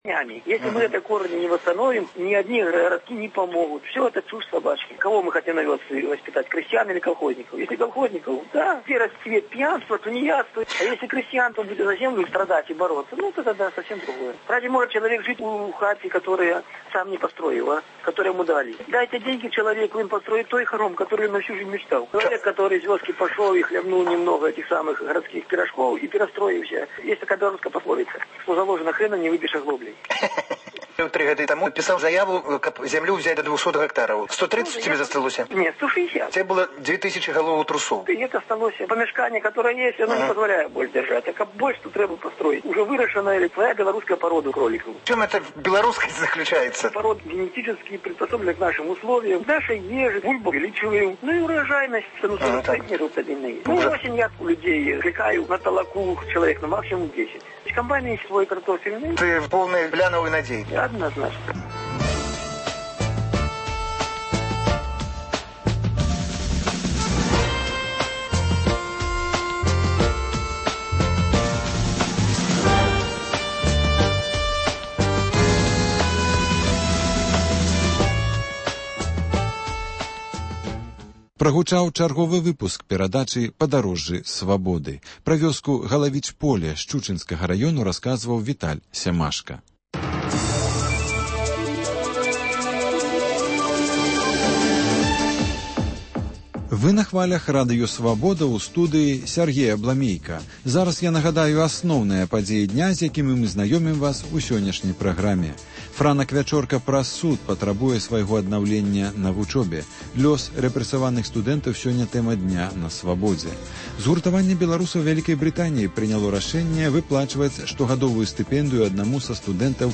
Працяг гутаркі зь гісторыкам